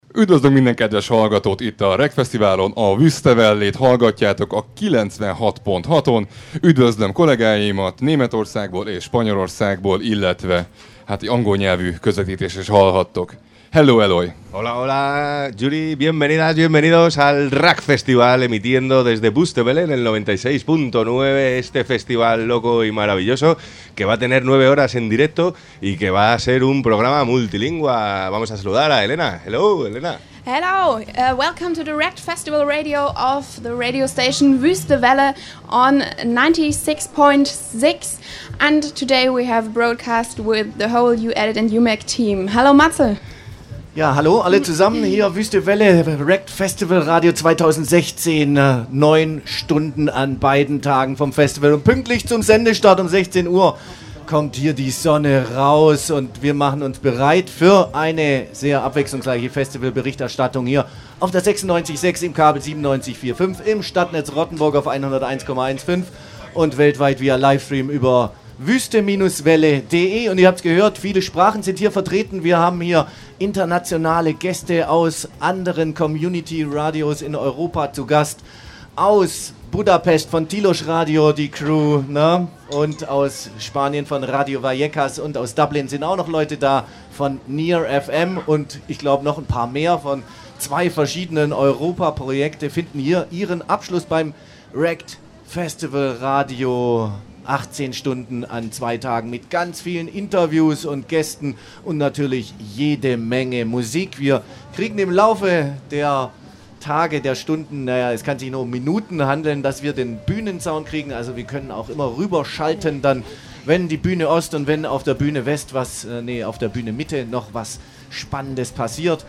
Mehrsprachig haben wir in 18 Stunden Livesendung das Festival begleitet und hatten dabei jede Menge Spaß.
Die mehrsprachige Begruessung bei unserer Sendung auf dem RAct 2016
1_opening_hungarian_spanish_english_german.mp3